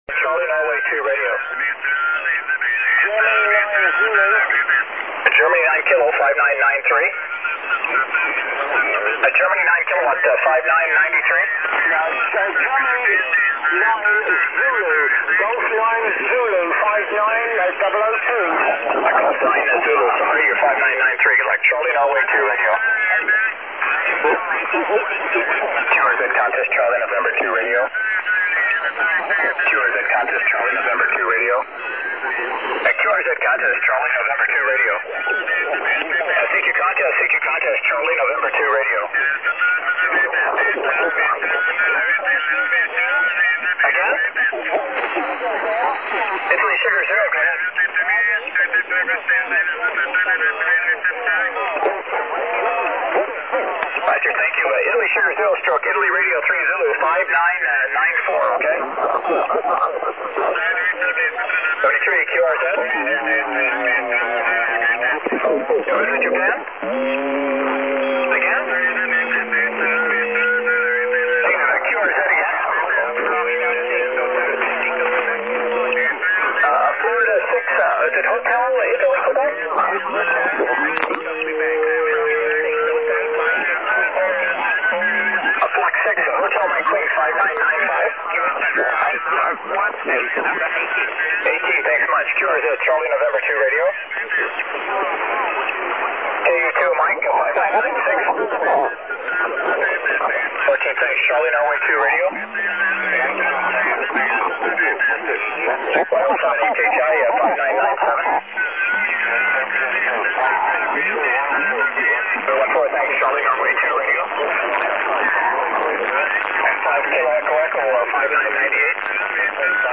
SSB-filter-live-sample-80m.mp3